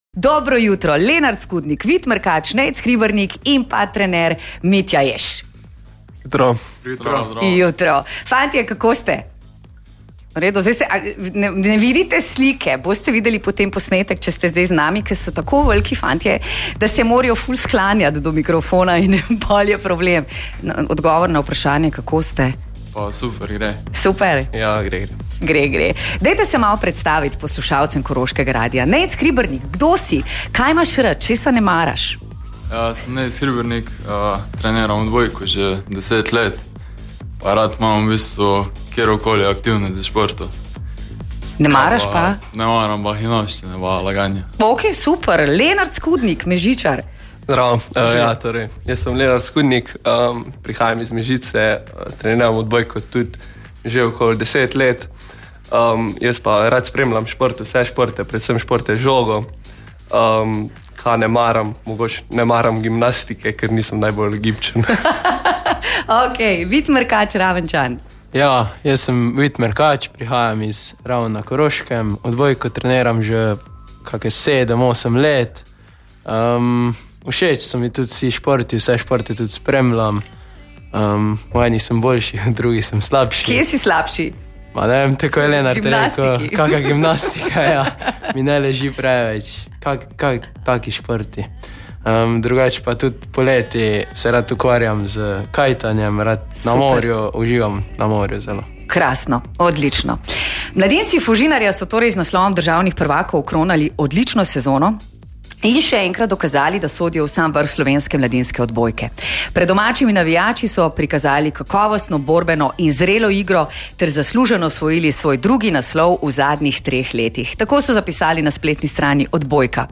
V studiu